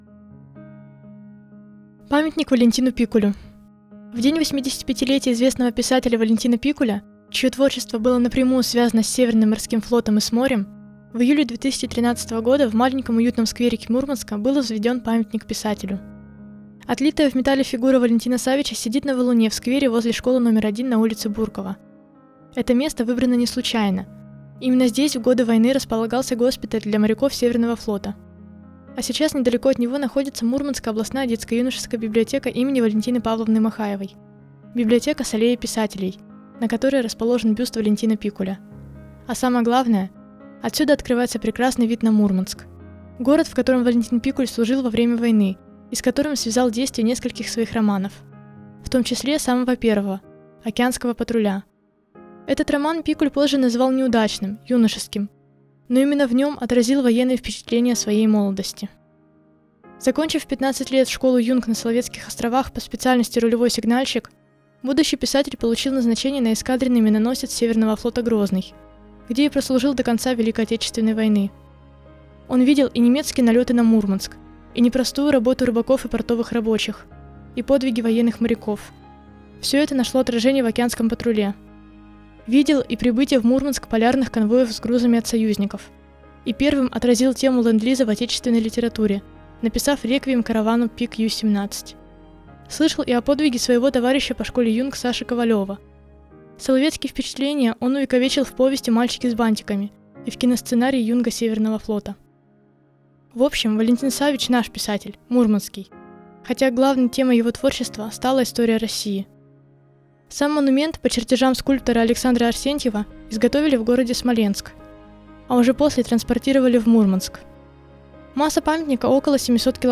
В честь этого знаменательного события волонтерами библиотеки подготовлена аудиоэкскурсия
Текст читает волонтер